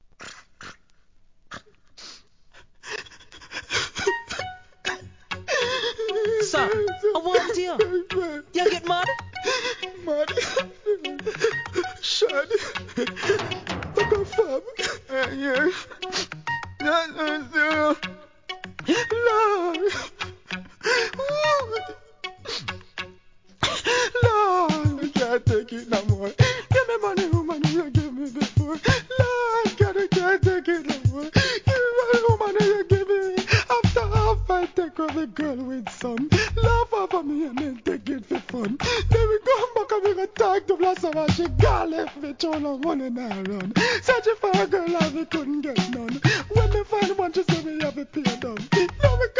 REGGAE
1991年、泣きながら歌うというインパクトある大ヒット!!